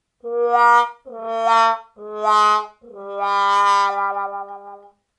На этой странице собраны звуки грустного тромбона (sad trombone) — узнаваемые меланхоличные мотивы, часто используемые в кино и юмористических роликах.
Грустный тромбон 2